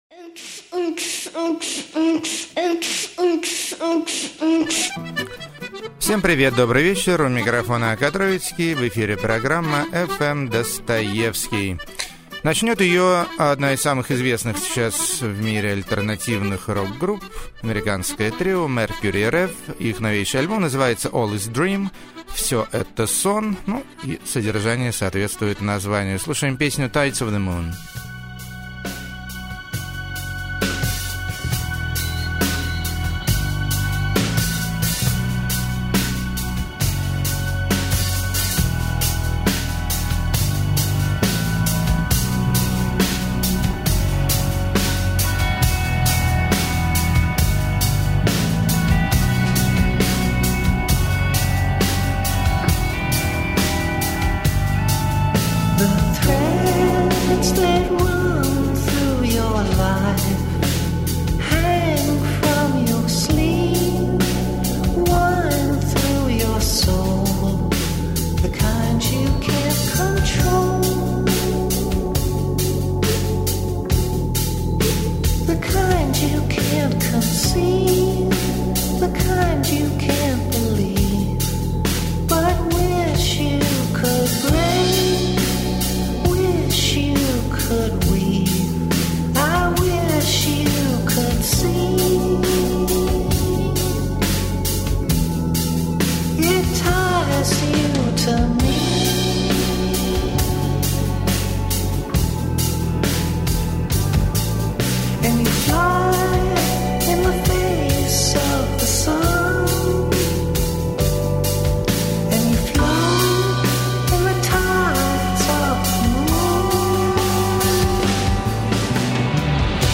Божественный Авант-симфо-рок.
Гаражно-гитарное Творчество Высшего Качества.
Между Lounge И Grundge.